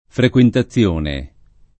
[ frek U enta ZZL1 ne ]